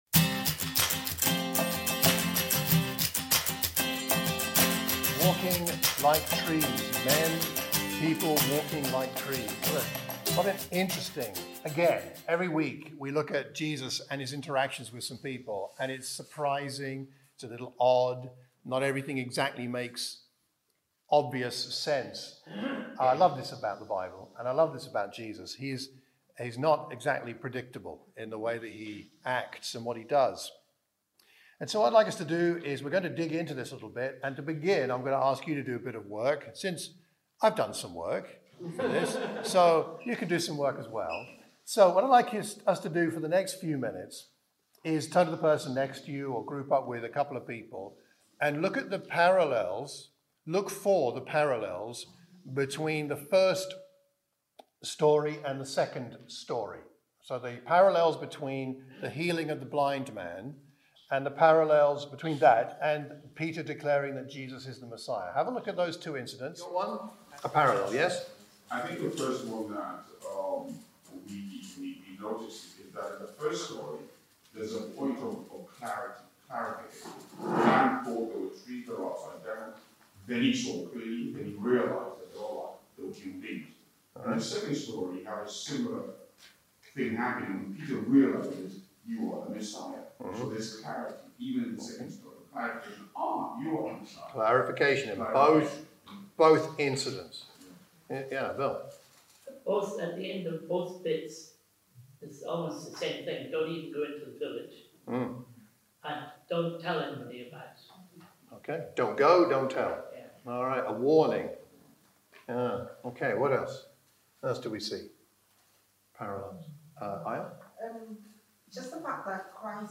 This was a sermon for the Watford church of Christ.